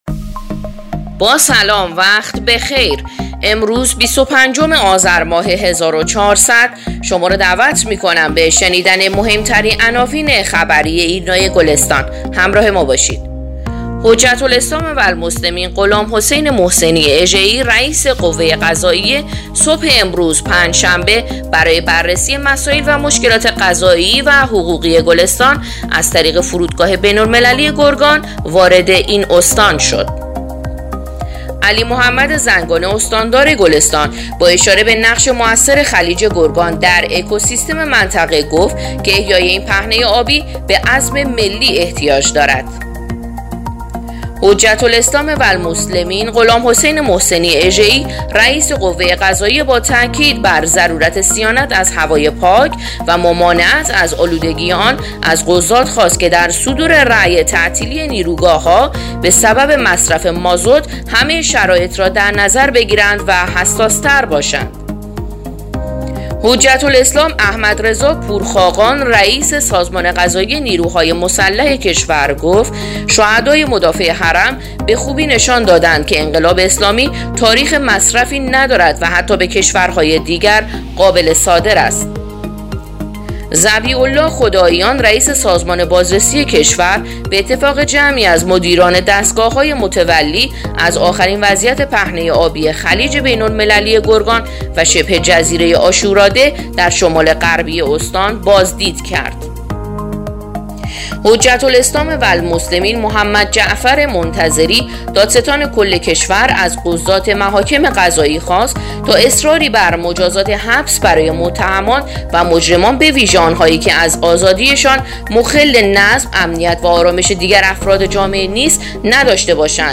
پادکست/ اخبار شبانگاهی بیست و پنجم آذر ایرنا گلستان